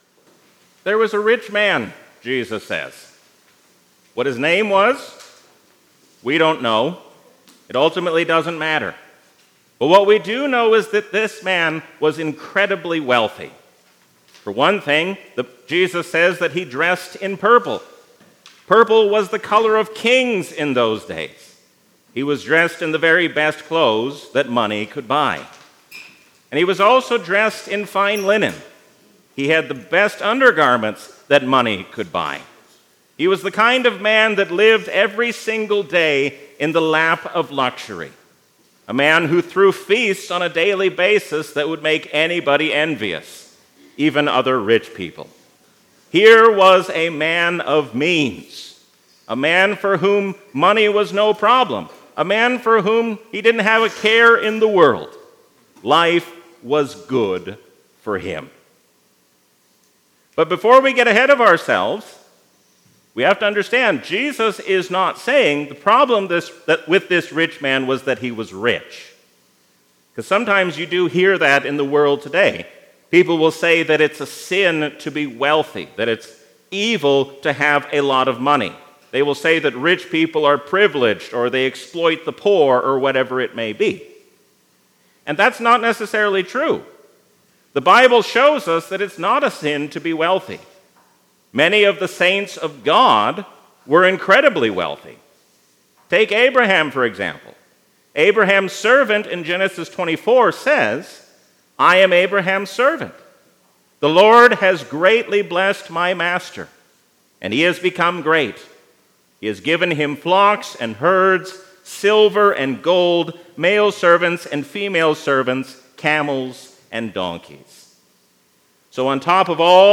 A sermon from the season "Trinity 2023." We are called to faithfully carry out the work given to us while we wait for the Last Day.